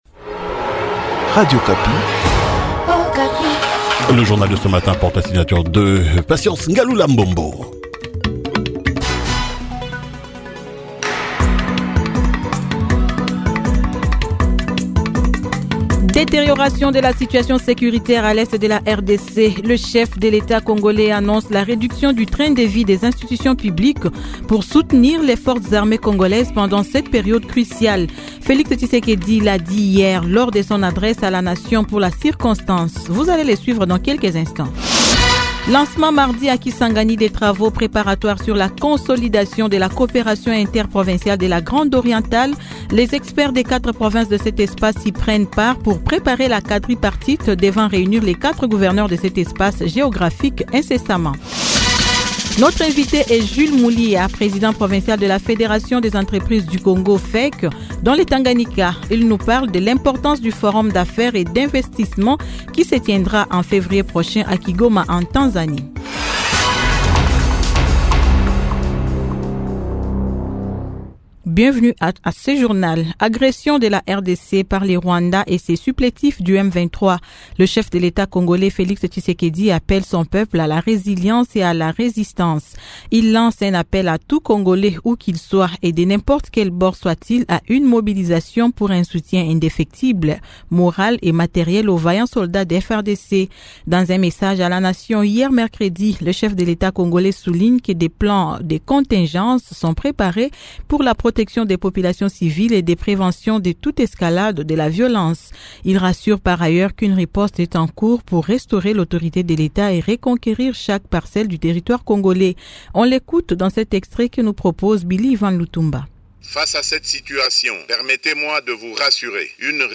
Journal matin 06h-07h de jeudi 30 Janvier 2025